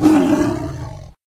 combat / creatures / tiger / he / attack1.ogg
attack1.ogg